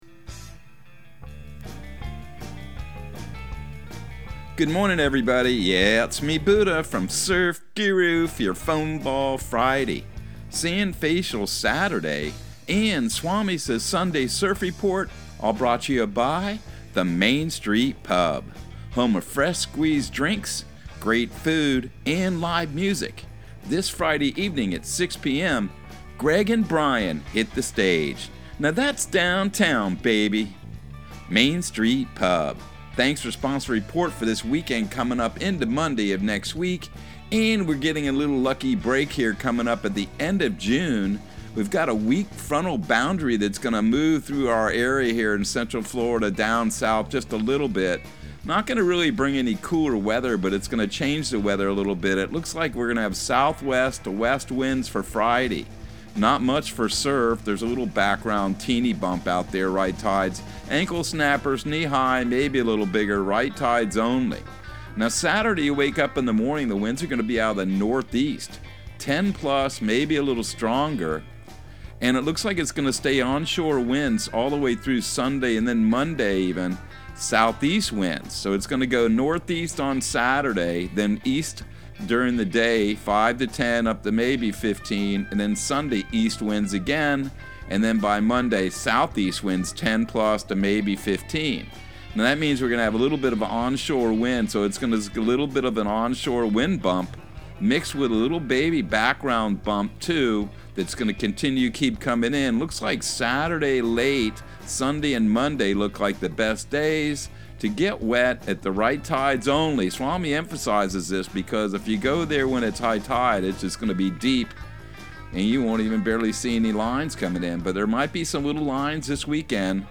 Surf Guru Surf Report and Forecast 06/24/2022 Audio surf report and surf forecast on June 24 for Central Florida and the Southeast.